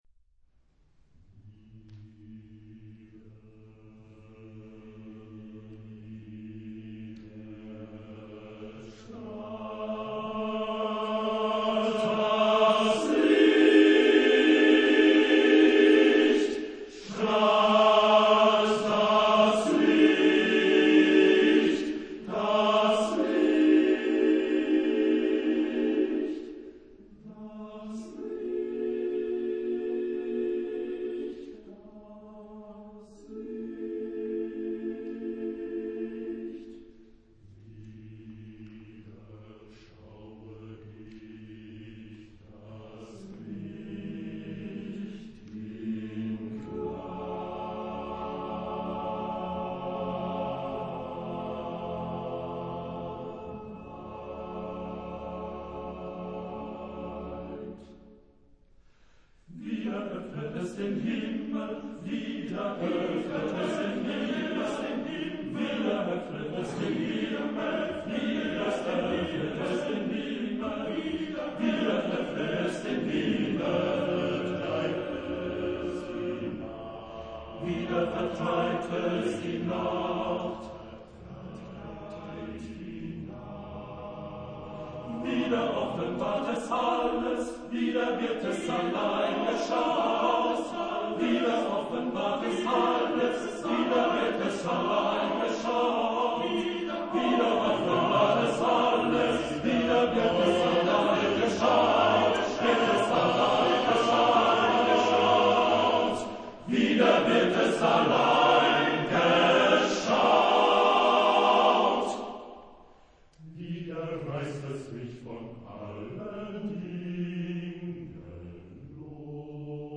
Genre-Stil-Form: Hymnus (geistlich)
Charakter des Stückes: feierlich ; verzückt
Chorgattung: TTTBBB  (6 Männerchor Stimmen )
Solisten: Ténors (3)